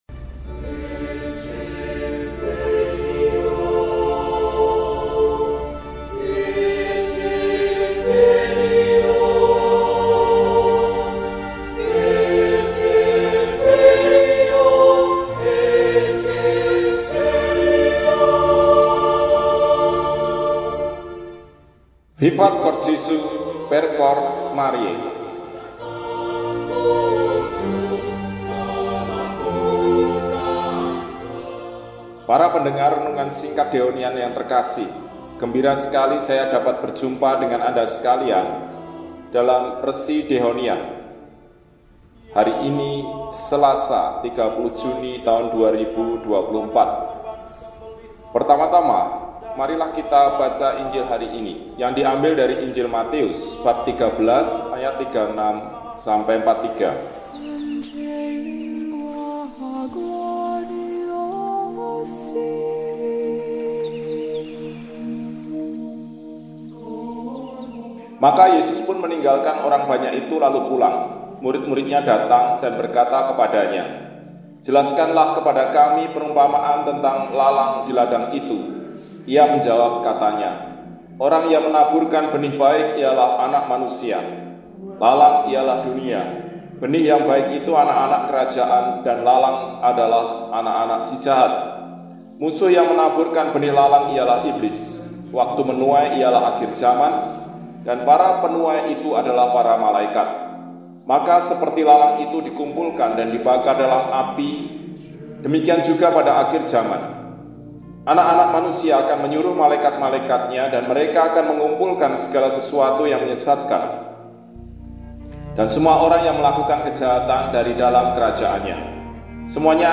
Selasa, 30 Juli 2024 – Hari Biasa Pekan XVII – RESI (Renungan Singkat) DEHONIAN